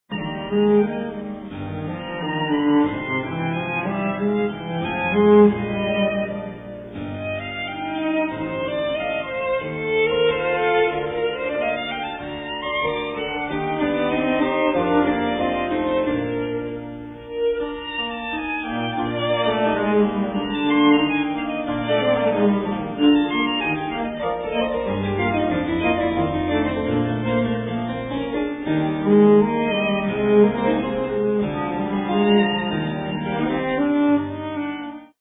for Violin, Cello and Harpsichord